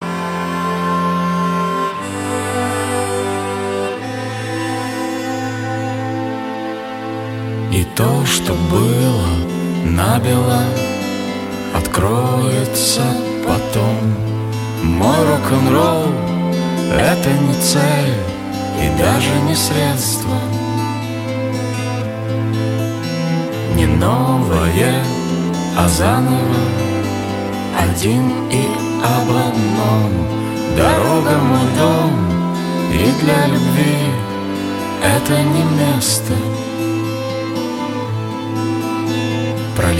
post-grunge
дуэт , скрипка
душевные